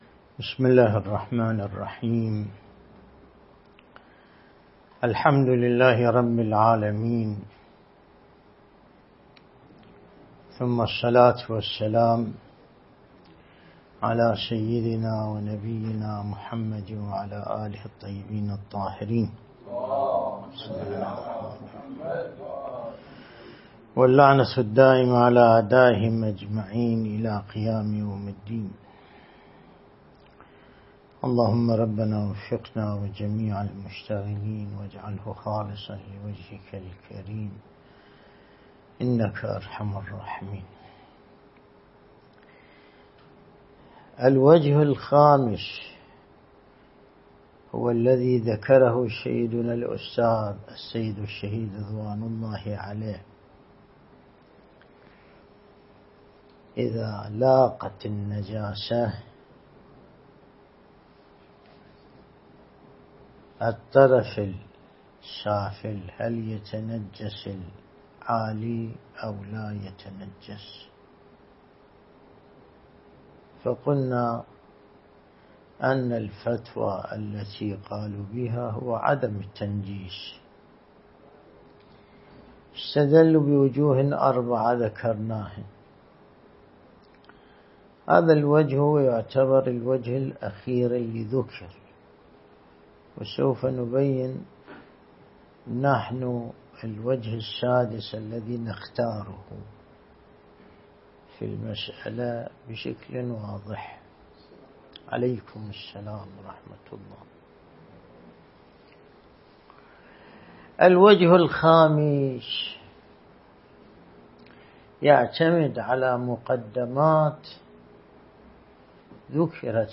الدرس الاستدلالي